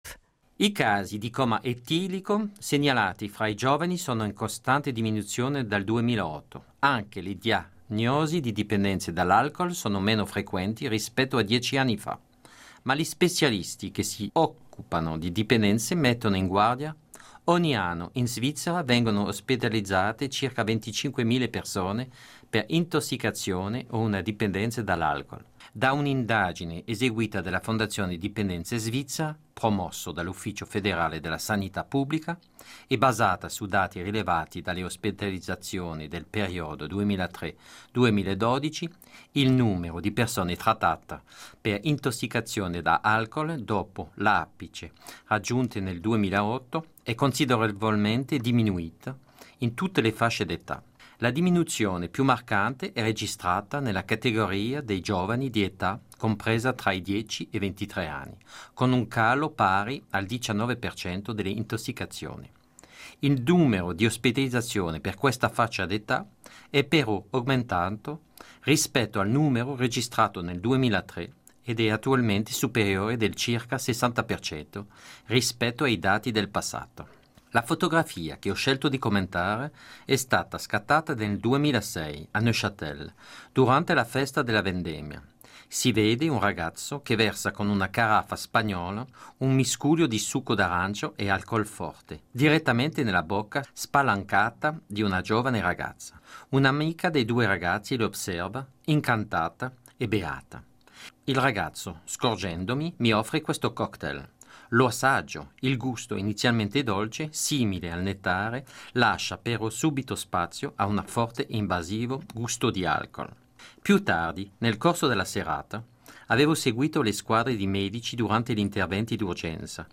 A radio comment about the image “Youth and Alcohol”.